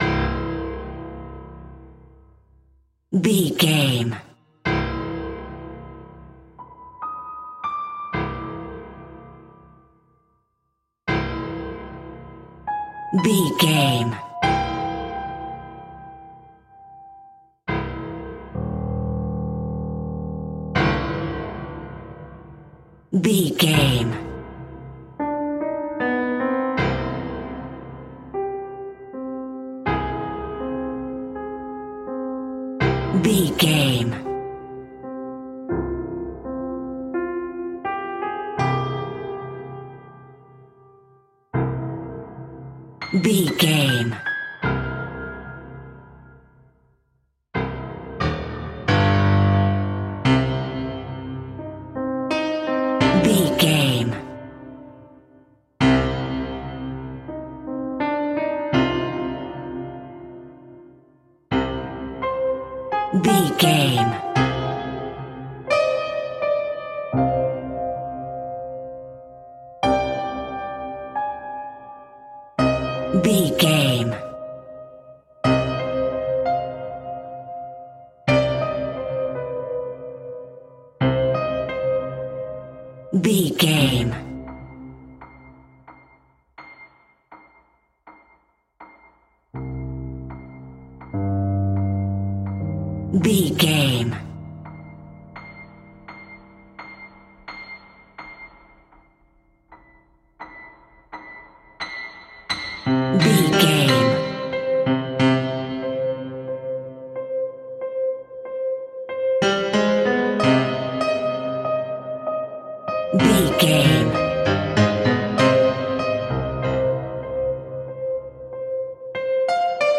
Aeolian/Minor
scary
ominous
suspense
haunting
eerie
percussion
horror